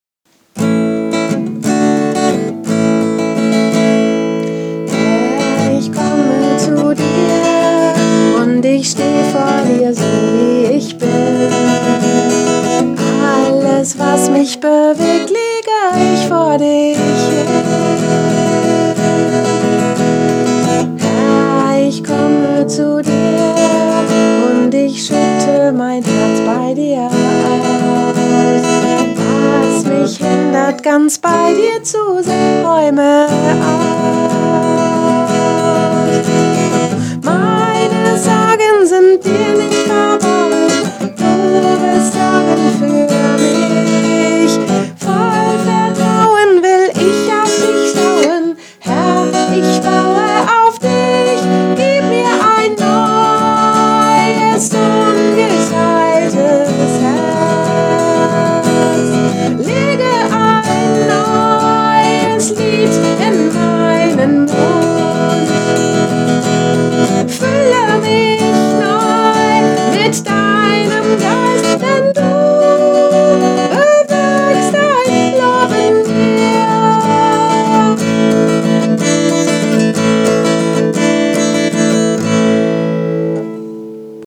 Musik für den Begegnungsabend am 24.04.2020